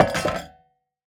metal.wav